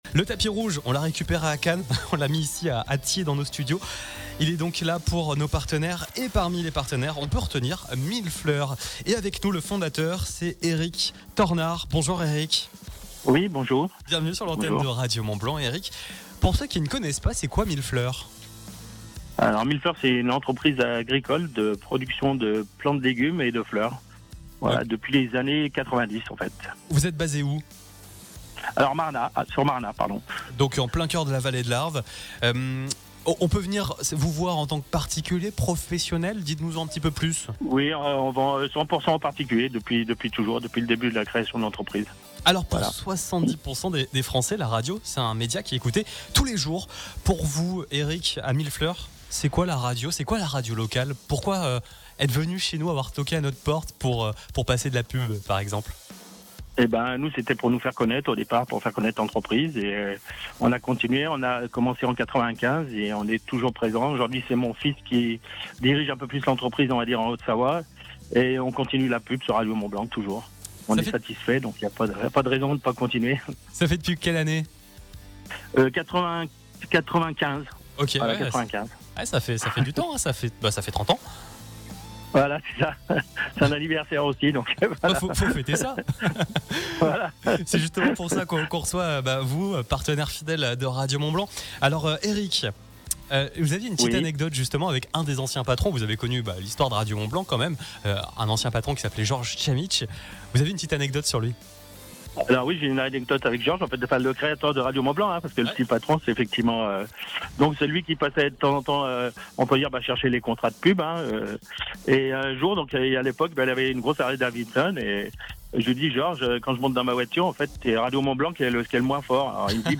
À l’occasion de notre soirée de remerciements clients, Radio Mont Blanc a déroulé le tapis rouge à ceux qui font vivre le territoire, innovent, entreprennent et nous font confiance tout au long de l’année.
Interview